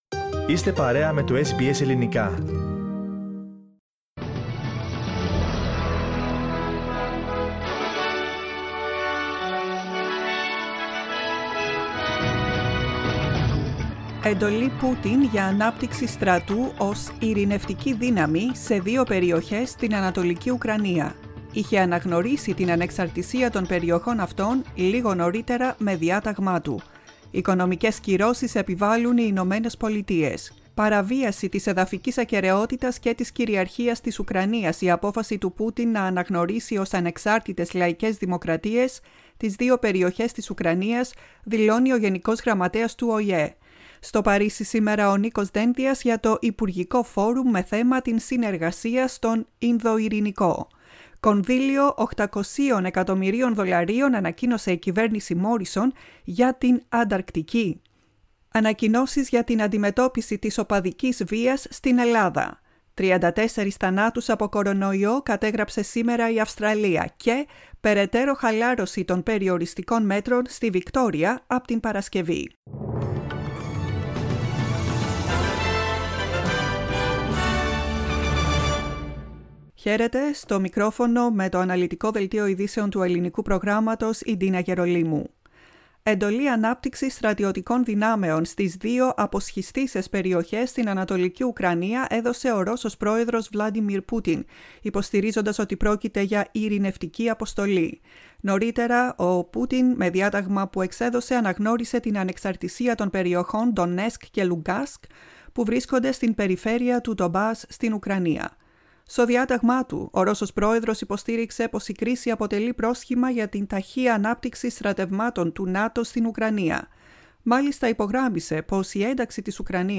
News bulletin in Greek, 22.02.22